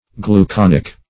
Gluconic \Glu*con"ic\, a.